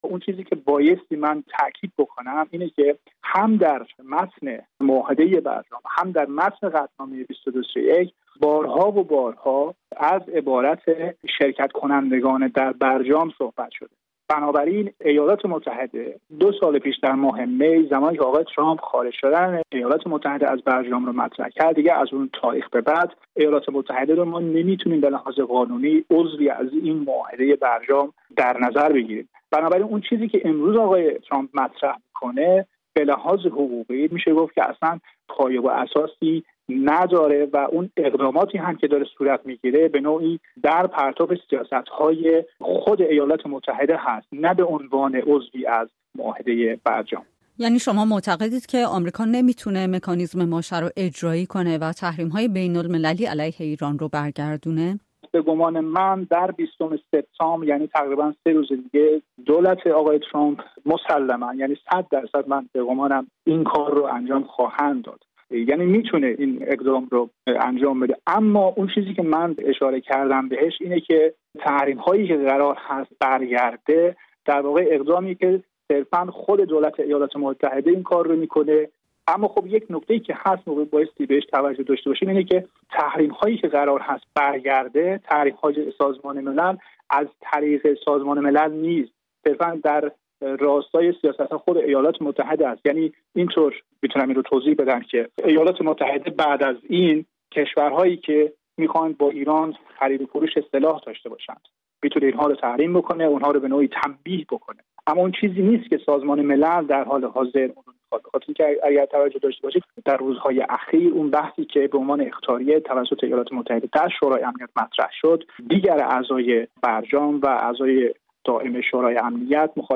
آیا آمریکا از نظر حقوقی امکان بازگرداندن تحریم‌های بین‌المللی علیه ایران را دارد؟ گفت‌وگوی